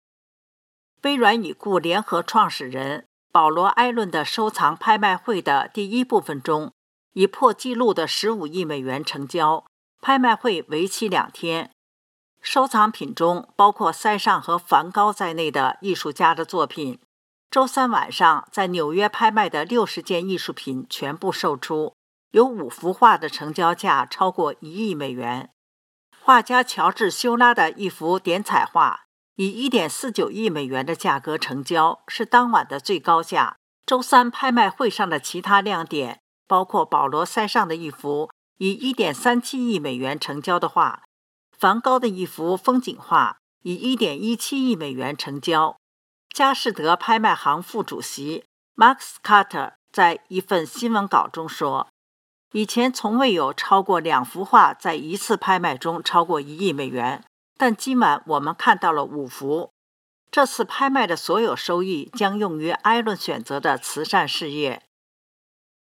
新聞廣播